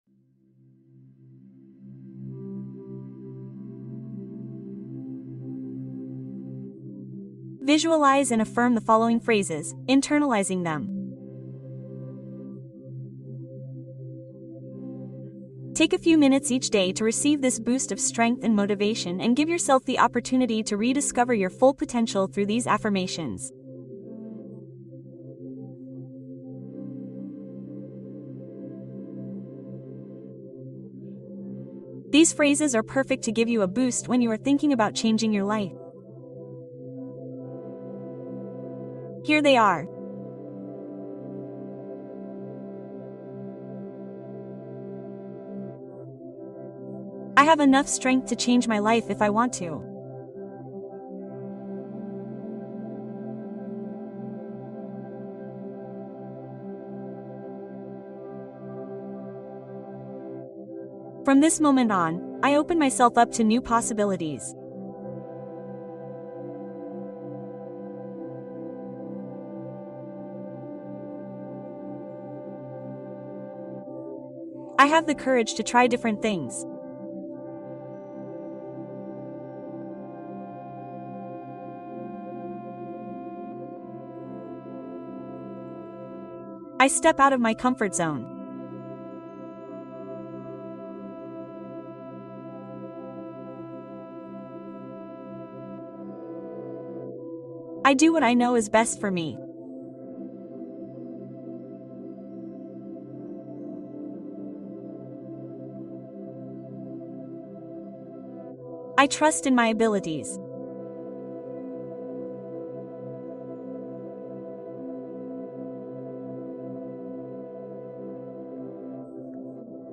Duerme como un bebé Cuento + meditación para sueño dulce